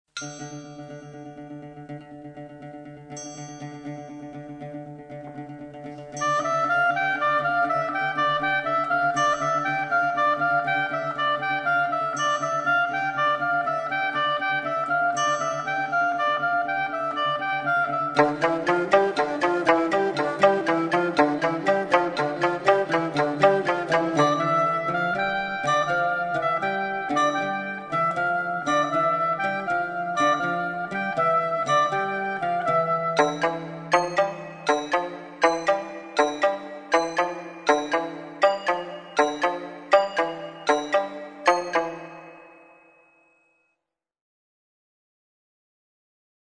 φωνές που ακούγονταν έξω από τη σχολή έχουν τώρα πυκνώσει ενώ ακούγονται καθαρά και κάποια συνθήματα όπως , κάθαρση!, θάνατο στους άθεους! , έξω ο Πυθαγόρας και το συνάφι του από τον Κρότωνα.